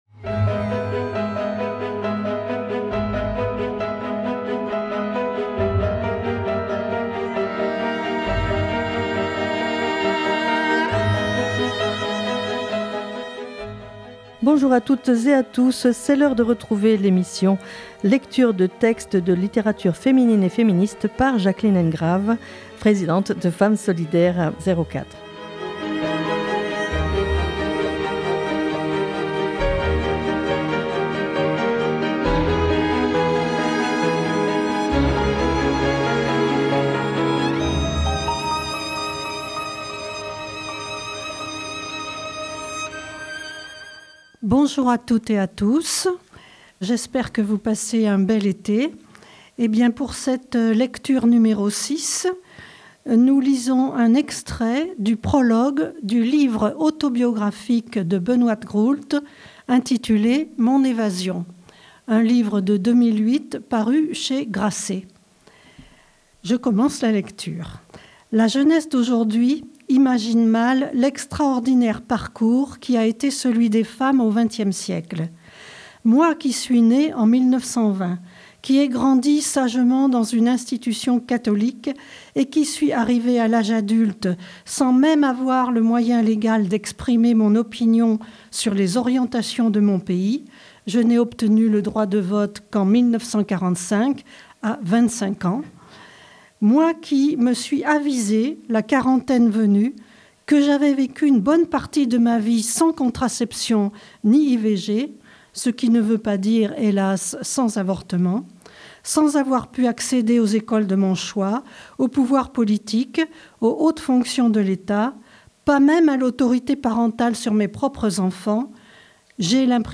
Lecture de textes de littérature féminine et féministe